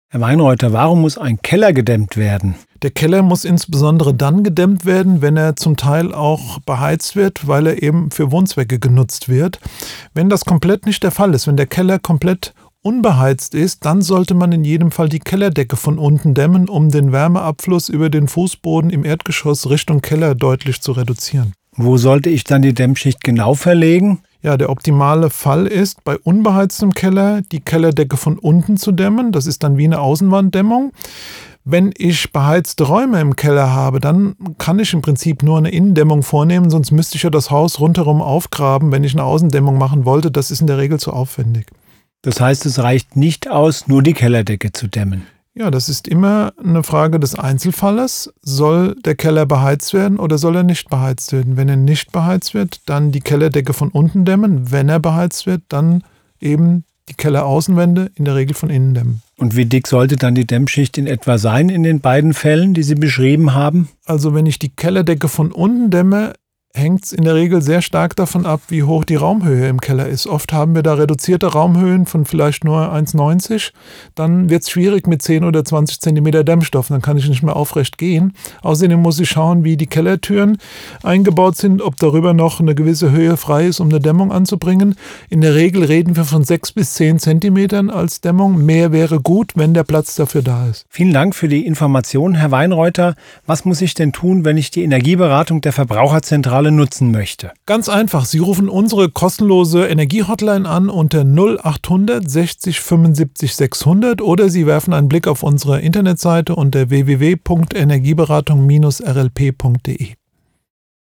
Interview zu wichtigen Energiethemen von A wie Atmende Wände über K wie Kellerdämmung bis W wie Wärmepumpe.